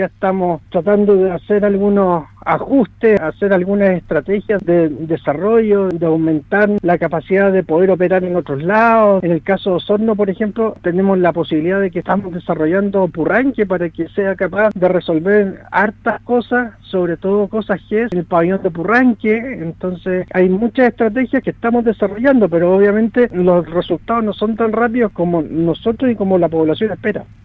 expresó en entrevista con Radio Sago su postura de que se realicen alianzas público privadas para avanzar en la concreción de estas cirugías.